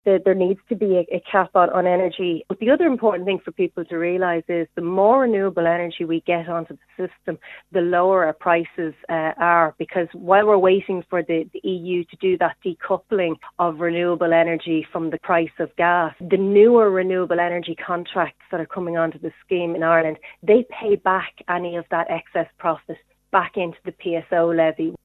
Sinn Féin Senator Lynn Boylan thinks when it comes to energy prices, caps need to be put in place: